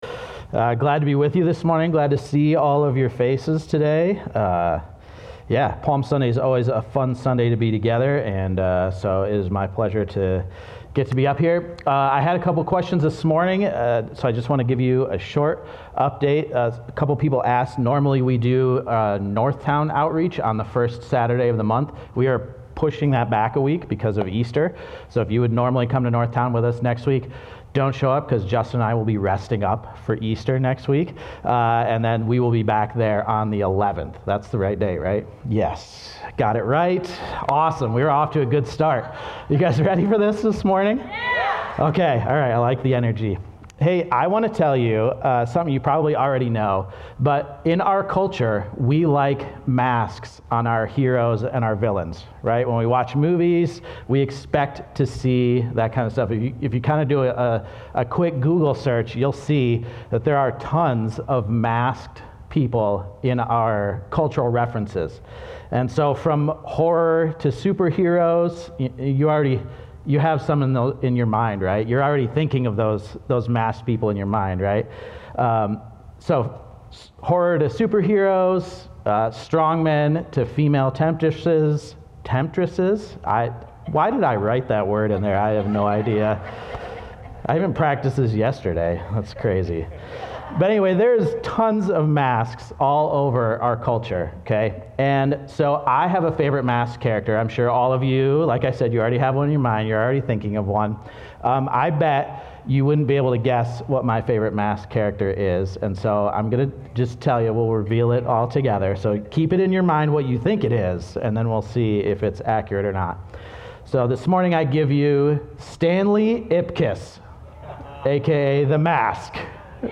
keyboard_arrow_left Sermons / Give It Up Series Download MP3